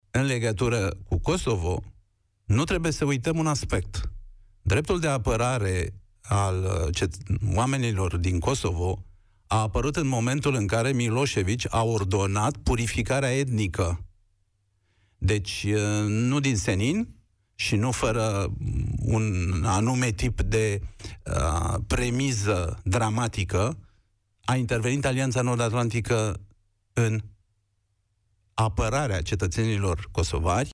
Cristian Diaconescu, fost ministru de externe al României, este invitatul lui Cătălin Striblea în emisiunea „România în Direct”.